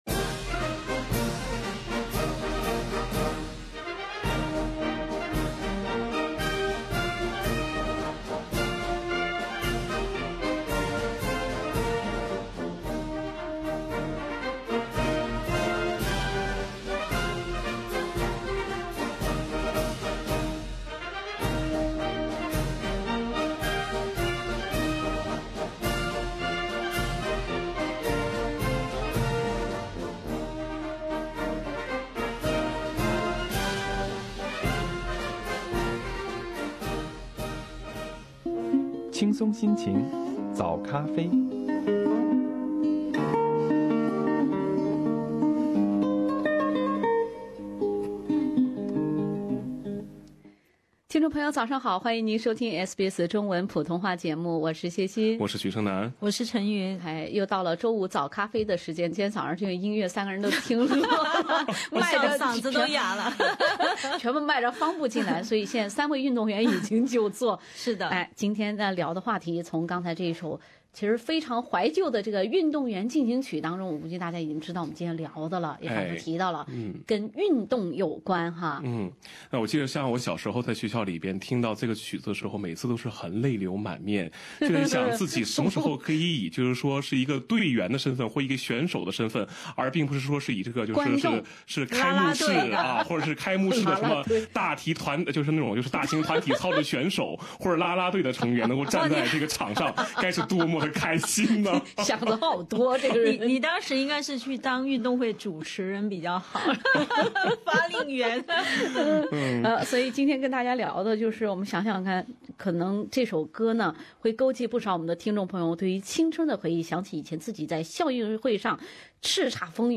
一首运动会开幕式入场进行曲勾起无限回忆。本期《早咖啡》，听众分享儿时的运动情怀。